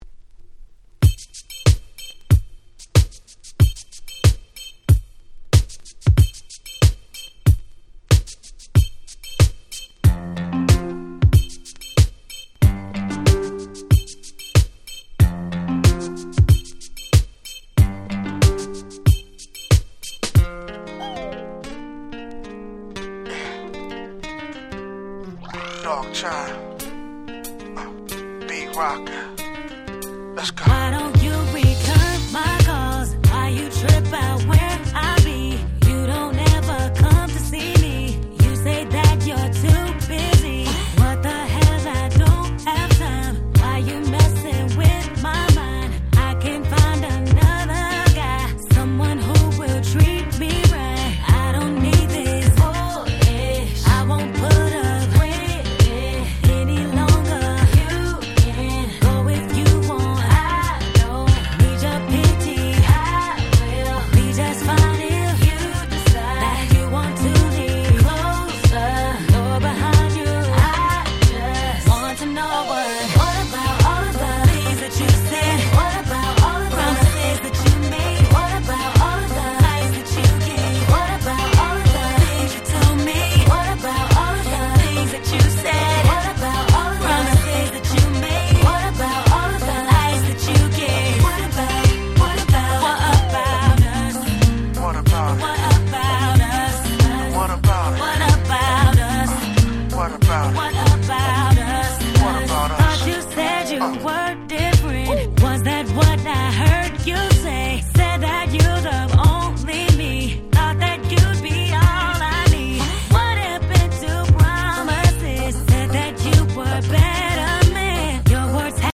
02' Super Hit R&B !!